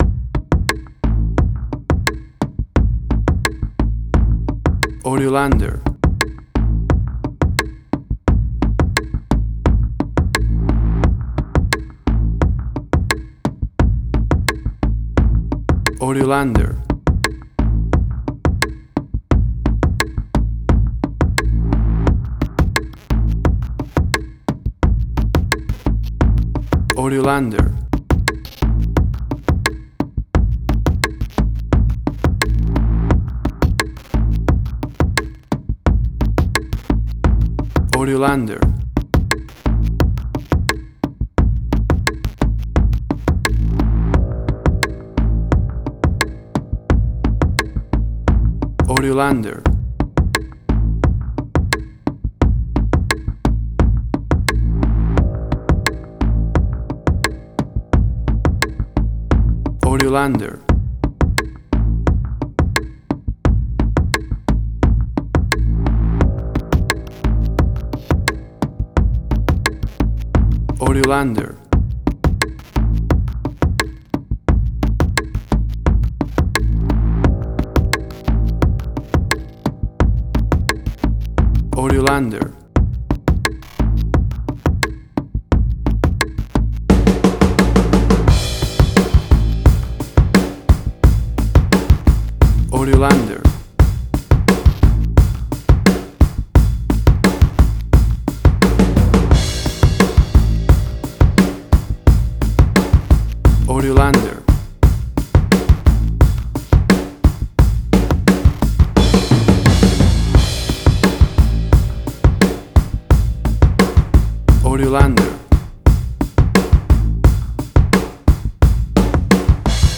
Suspense, Drama, Quirky, Emotional.
WAV Sample Rate: 16-Bit stereo, 44.1 kHz
Tempo (BPM): 87